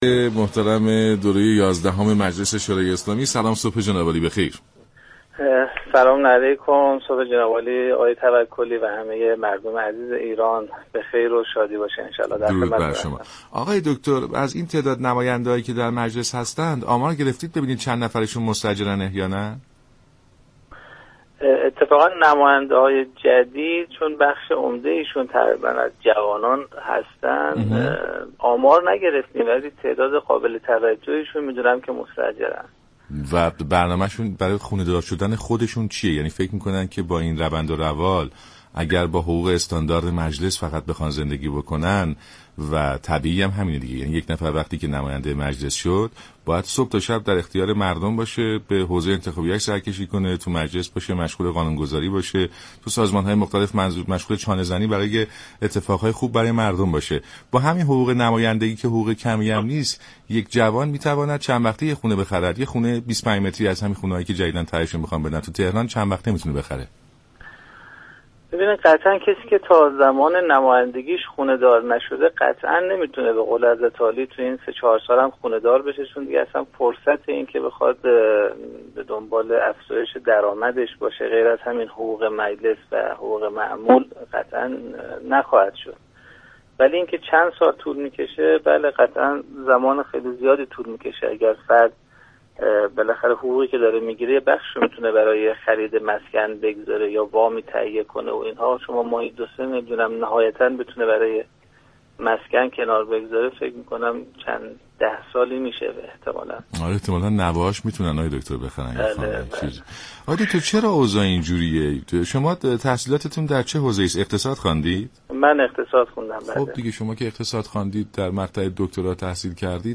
به گزارش شبكه رادیویی ایران، محسن زنگنه نماینده مردم تربت حیدریه در مجلس شورای اسلامی در گفت و گو با برنامه سلام صبح بخیر رادیو ایران اظهار كرد: بیشتر نمایندگان جدید مجلس به دلیل جوان بودن، مستاجر هستند اما امار دقیقی نداریم.